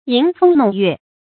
吟風弄月 注音： ㄧㄣˊ ㄈㄥ ㄋㄨㄙˋ ㄩㄝˋ 讀音讀法： 意思解釋： 吟：吟詠；指作詩；弄：玩弄；玩賞；泛指自然景物。